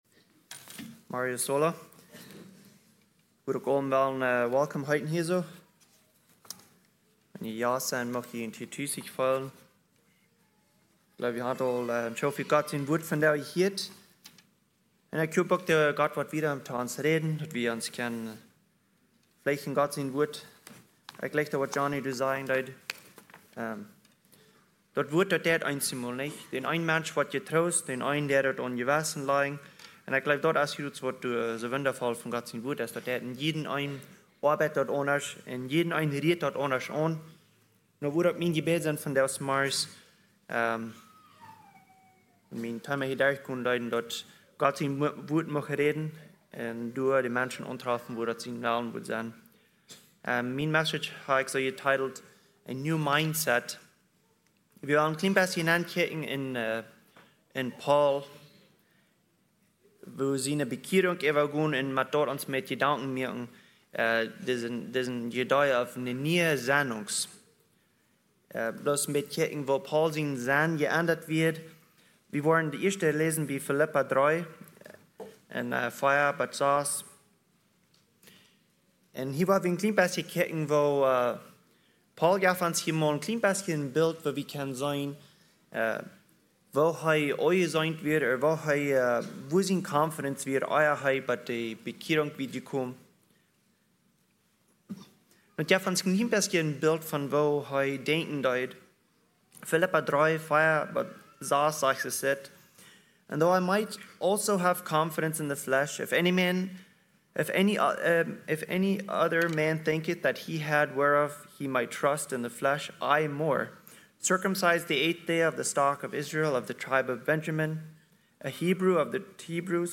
message brought on June 1, 2025